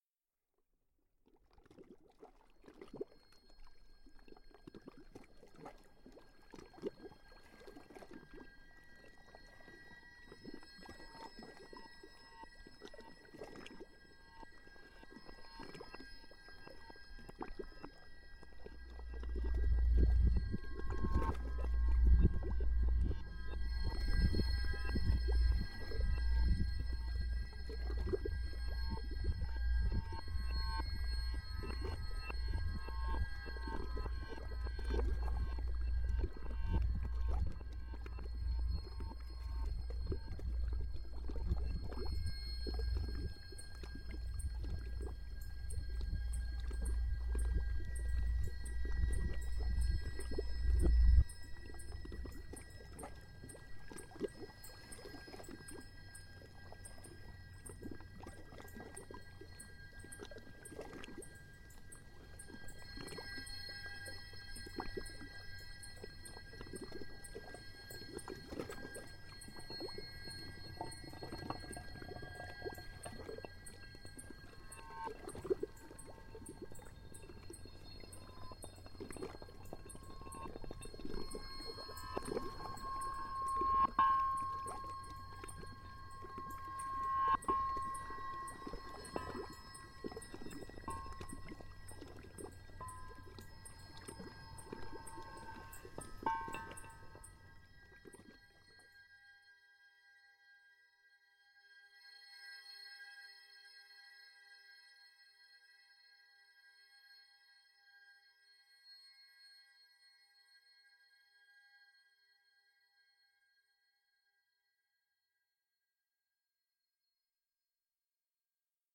reversed stones, throat of the dock, crickets